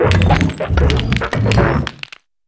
Cri d'Amassel dans Pokémon HOME.